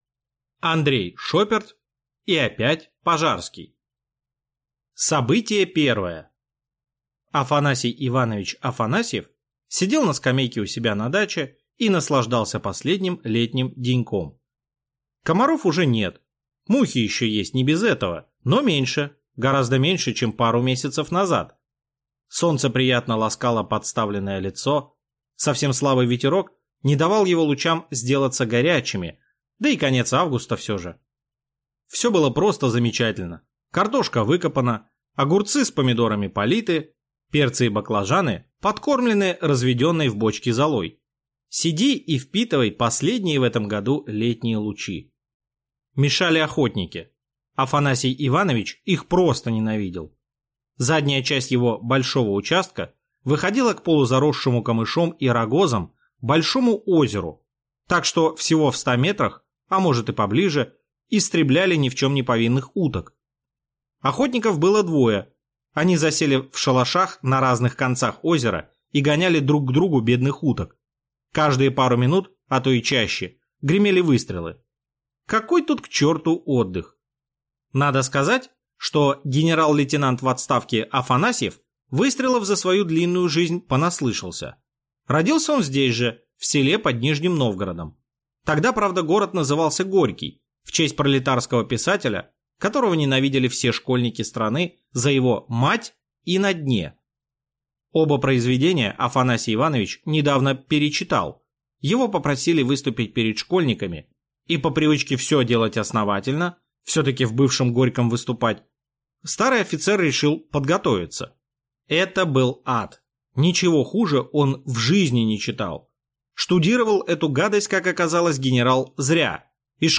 Аудиокнига И опять Пожарский | Библиотека аудиокниг